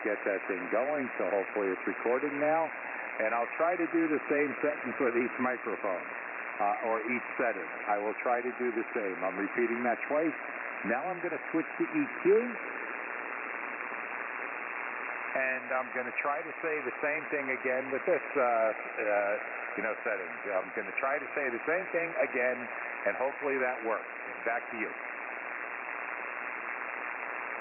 All of the tests were conducted in the HF bands including 80, 40 and 10 meters.
• Sounds fuller with EQ off
• Mids increased when EQ is on
Electro Voice RE-320 EQ Off Then On
EV-RE-320-EQ-Off-Then-On.mp3